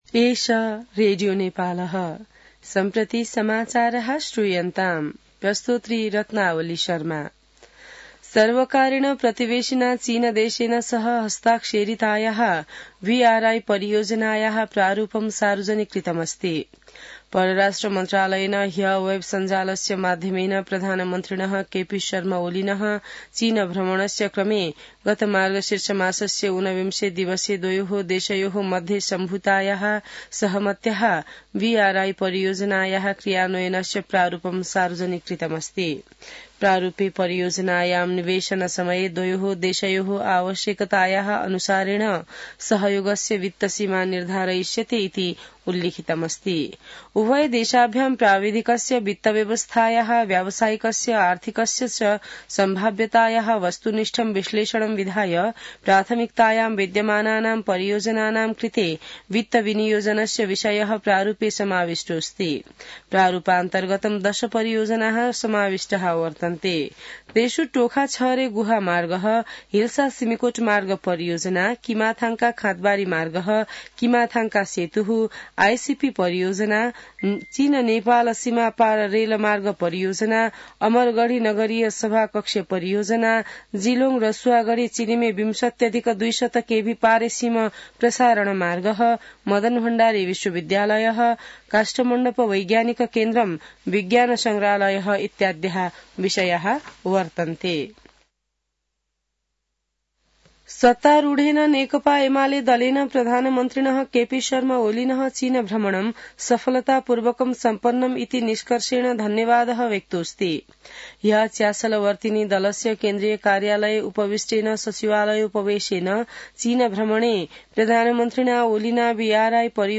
संस्कृत समाचार : २७ मंसिर , २०८१